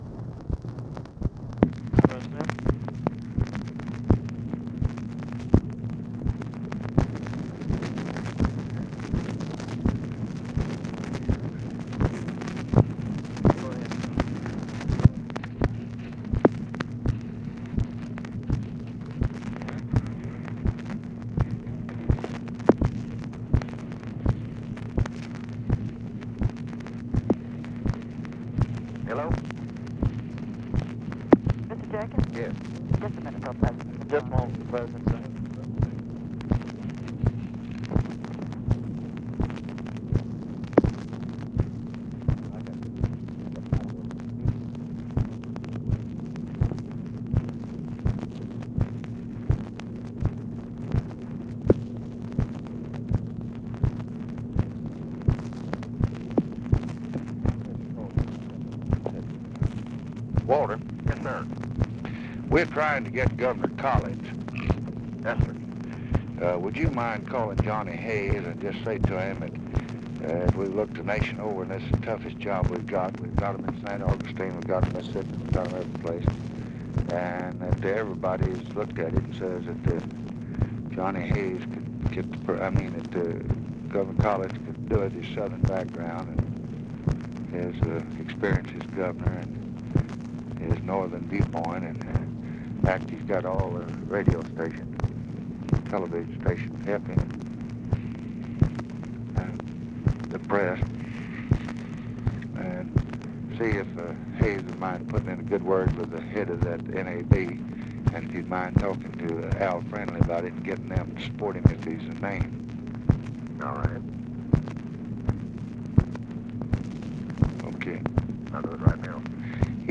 Conversation with WALTER JENKINS, June 26, 1964
Secret White House Tapes